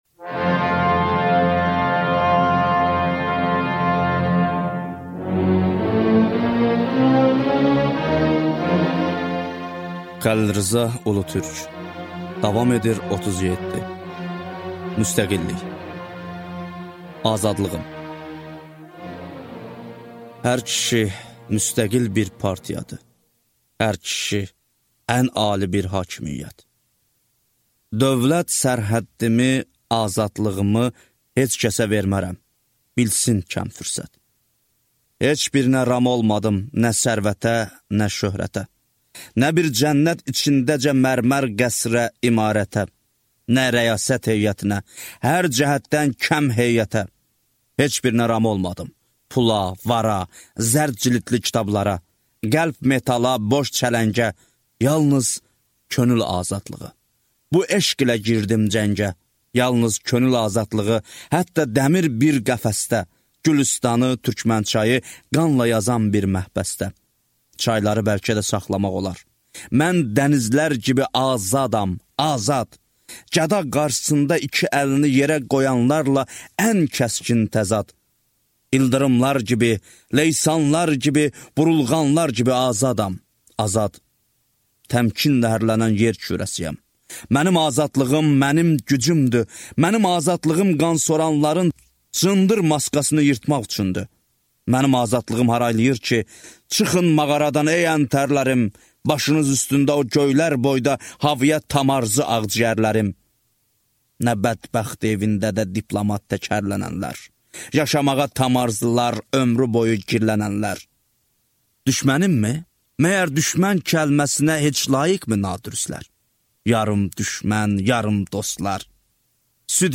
Аудиокнига Davam edir 37 | Библиотека аудиокниг